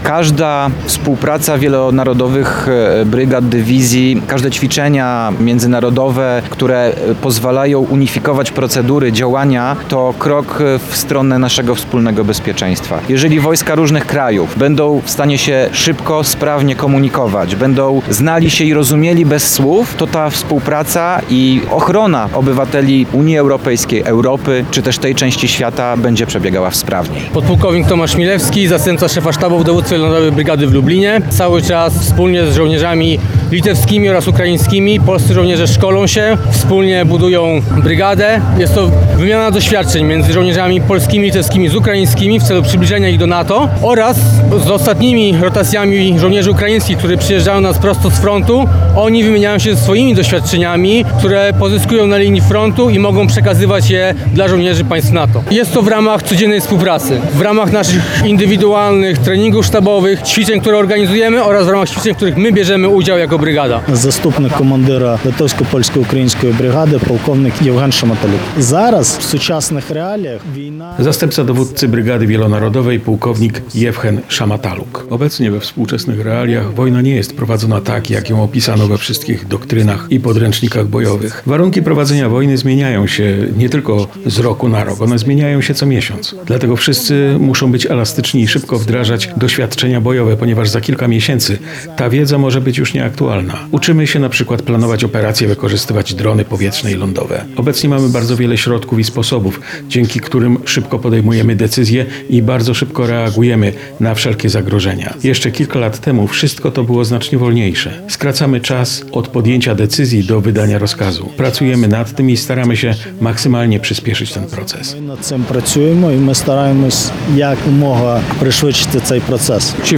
Pododdziały Sił Zbrojnych Litwy, Polski i Ukrainy świętują dziś (19.09) 10-lecie Wielonarodowej Brygady LITPOLUKRBRIG. Żołnierze, władze państwowe oraz samorządowe, a także mieszkańcy licznie zgromadzili się na placu Litewskim w Lublinie, by uczcić dekadę międzynarodowej wojskowej współpracy.